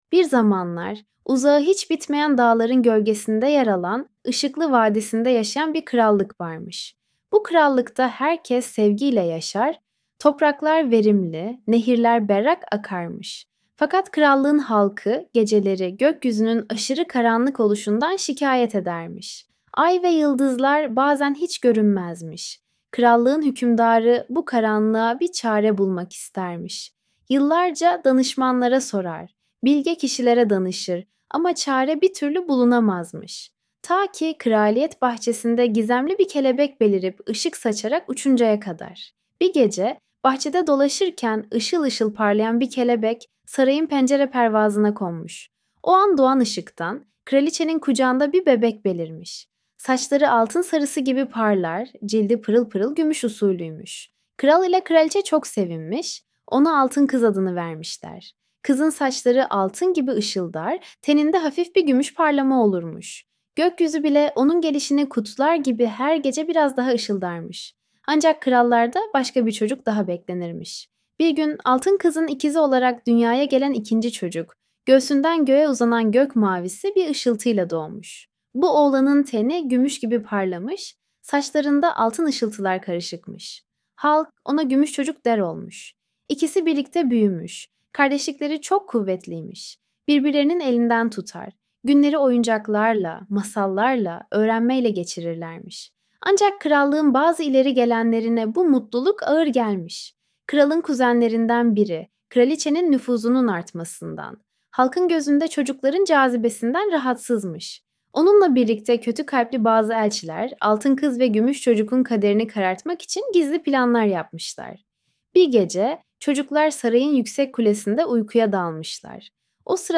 altin-kiz-ve-gumus-cocuk-sesli-masal.mp3